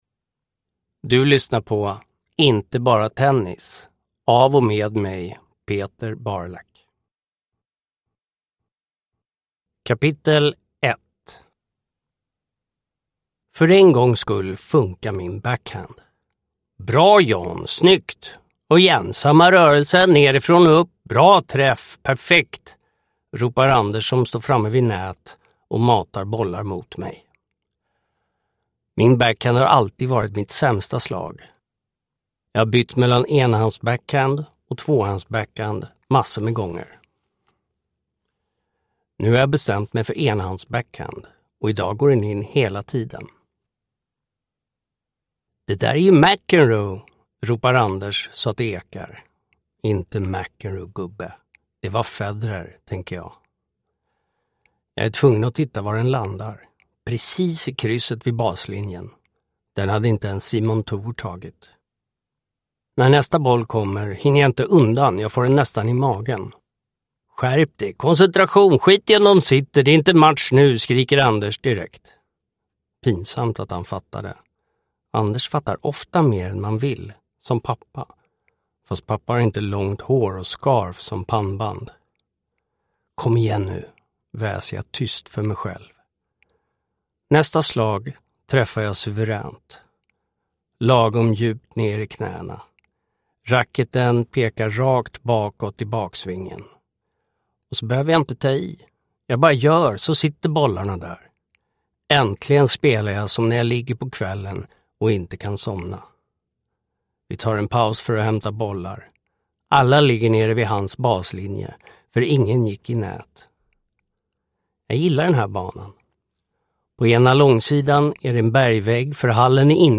Inte bara tennis – Ljudbok – Laddas ner